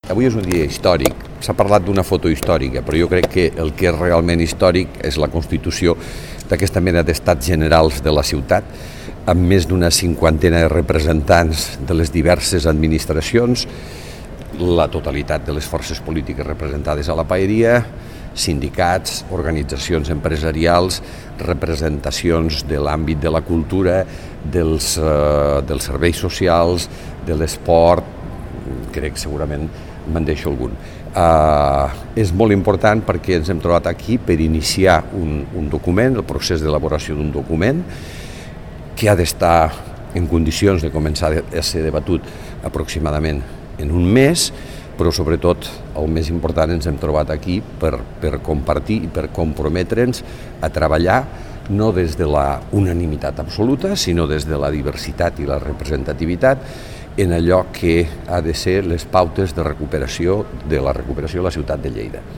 tall-de-veu-del-paer-en-cap-miquel-pueyo-sobre-la-constitucio-de-la-mesa-transversal-per-a-la-recuperacio-de-la-ciutat